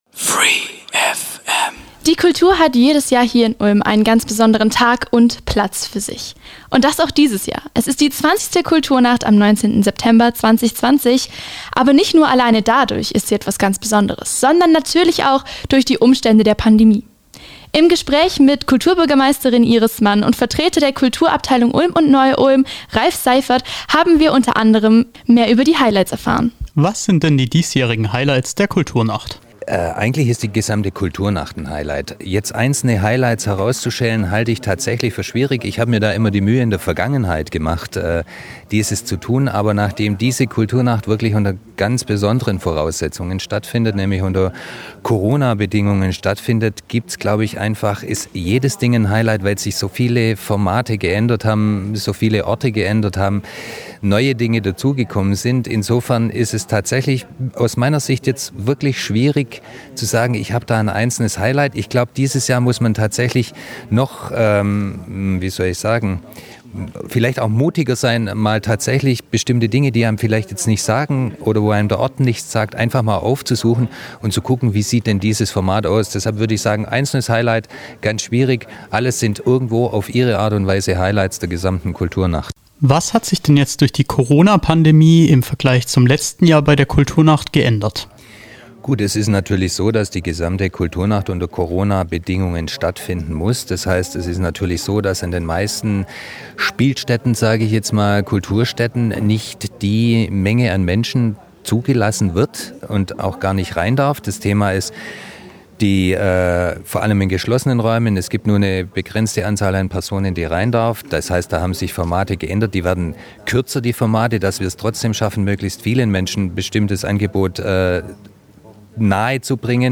Ist die Kulturnacht eigentlich eine Großveranstaltung? Auch das verraten sie uns im Gespräch!